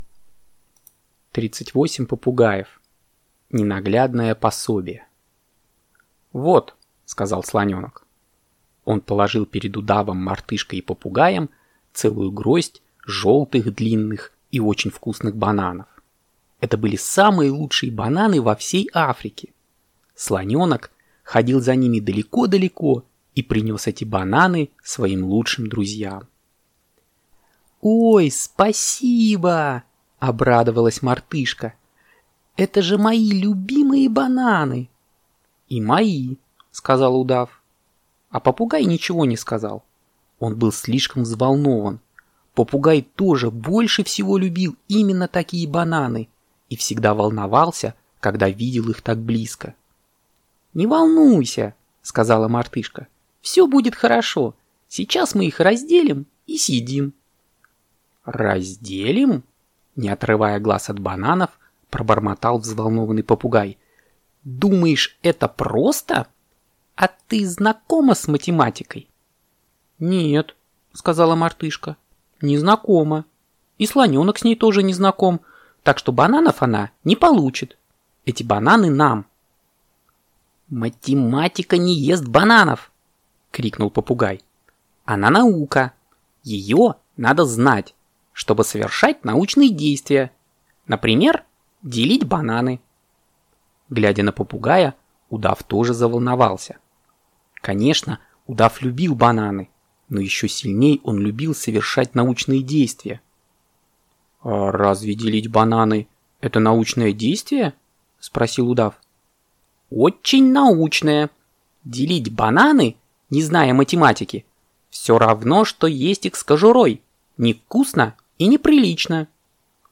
Ненаглядное пособие - аудиосказка Григория Остера - слушать онлайн